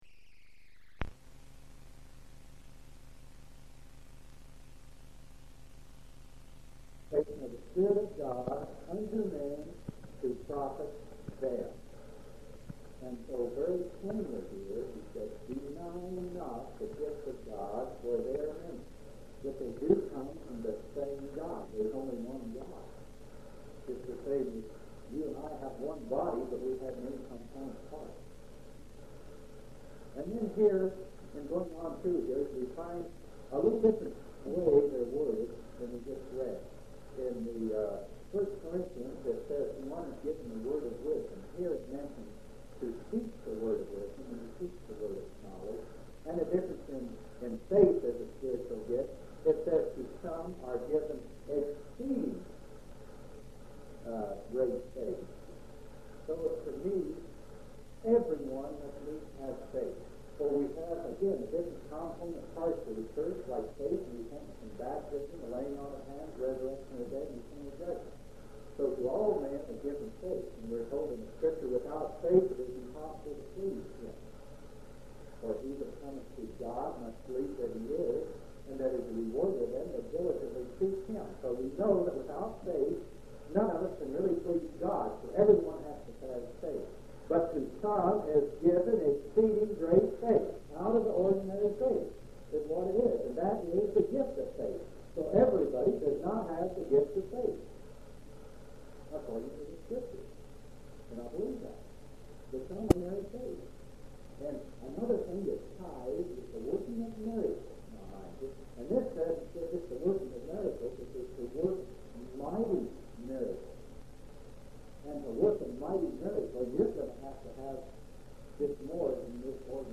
8/1/1976 Location: Grand Junction Local Event